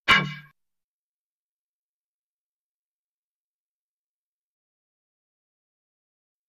Air Guns | Sneak On The Lot
Bamboo Dart Gun; Hollow, Air Whoosh, Fast, Processed.